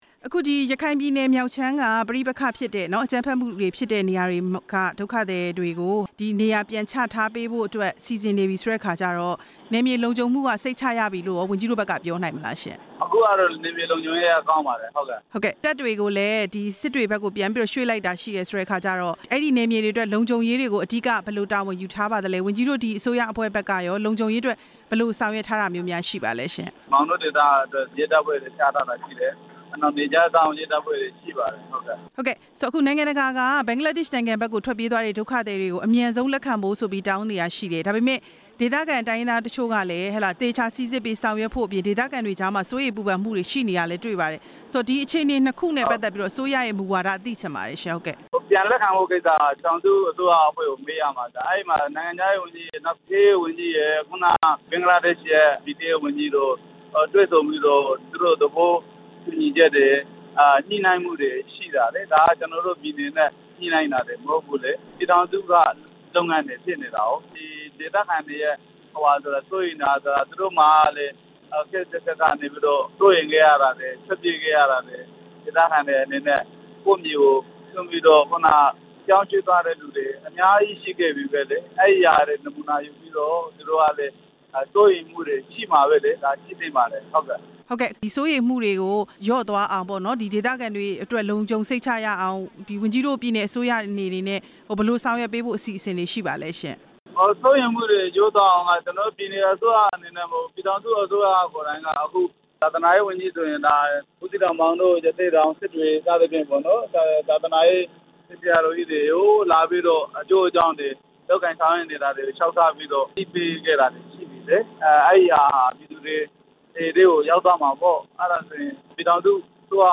အကြမ်းဖက်ခံ ရခိုင်မြောက်ပိုင်း လုံခြုံရေးအခြေအနေ မေးမြန်းချက်